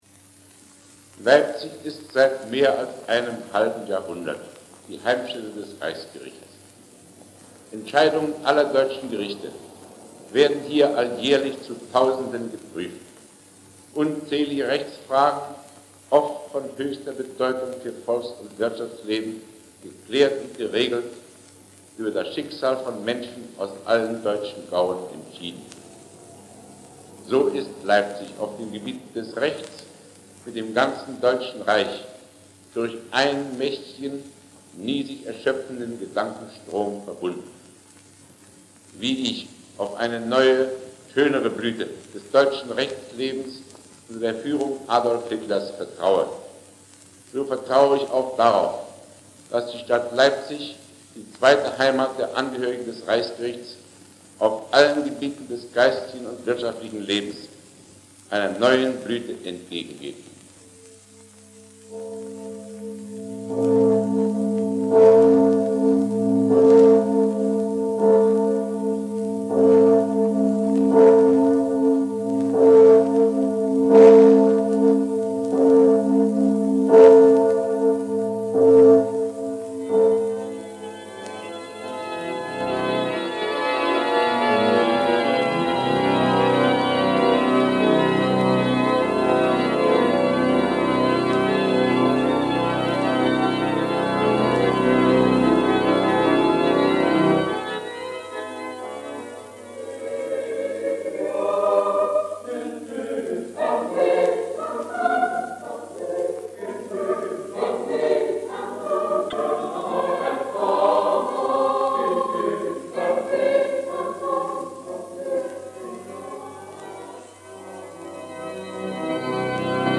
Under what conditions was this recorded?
Original Format: Shellac Record 78rpm